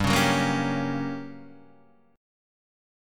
G Minor Major 7th Flat 5th